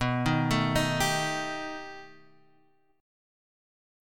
B+7 chord